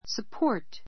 support 中 A2 səpɔ́ː r t サ ポ ー ト 動詞 ❶ （下から） 支える ; （人・意見などを） 支持する, 支援 しえん する This chair won't support his weight.